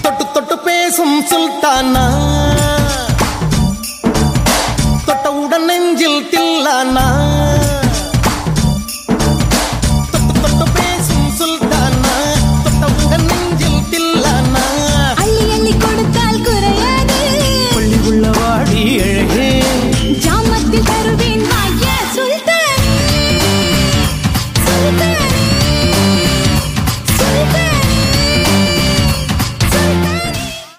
Ringtone File
Tamil Songs